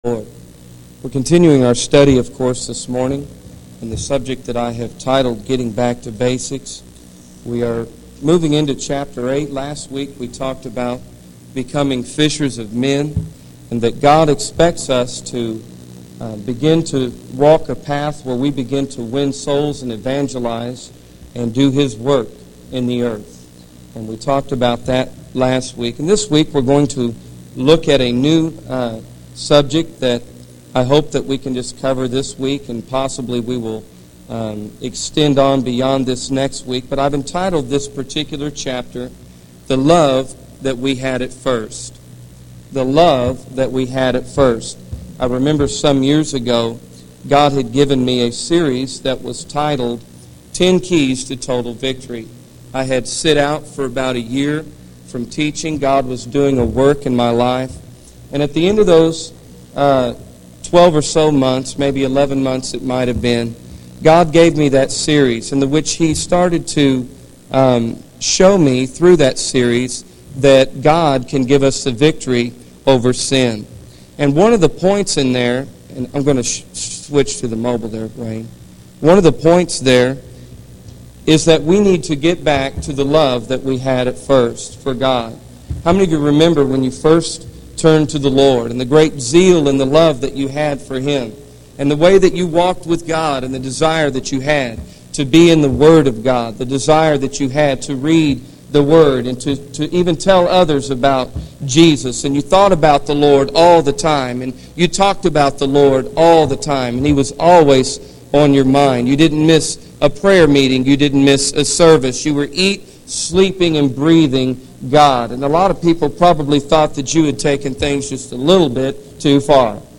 In this sermon, the speaker continues their study on getting back to basics. They discuss the importance of becoming fishers of men and evangelizing to win souls for God.